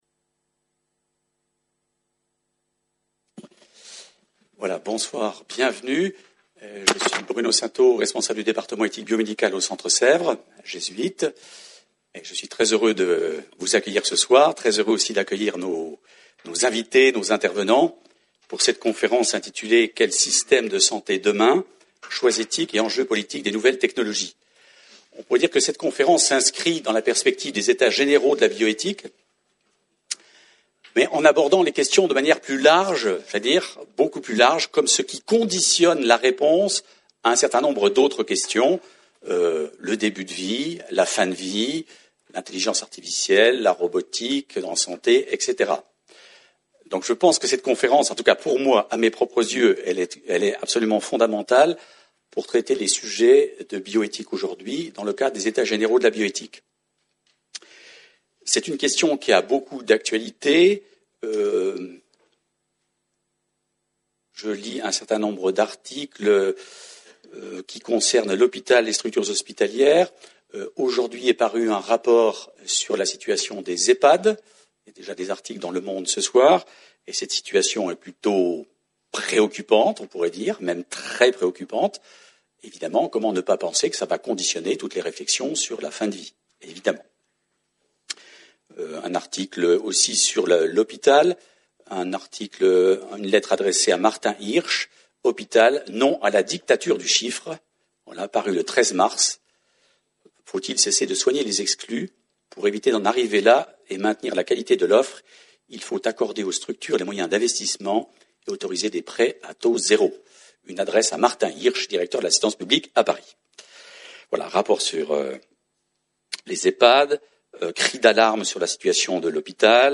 soireee-deb-systeme-sante-demain.mp3